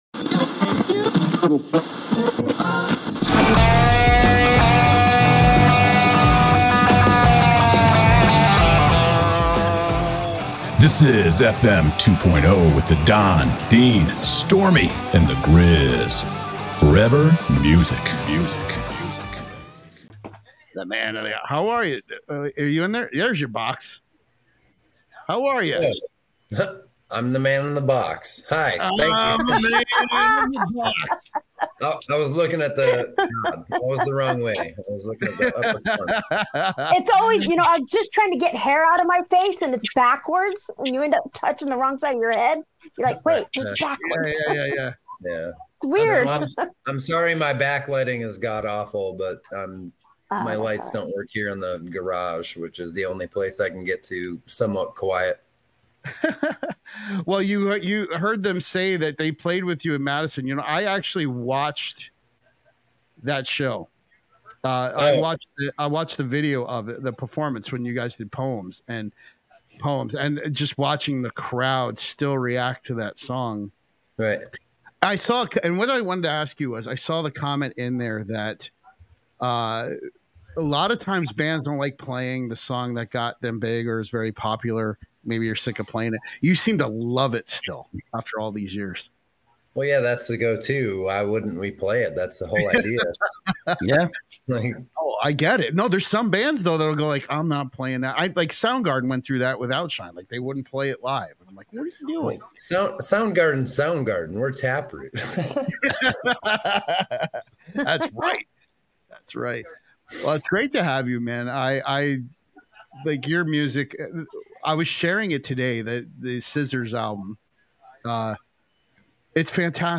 Stephen Richards of the alternative metal band TAPROOT joined the show and we talked about their recent performance of their huge hit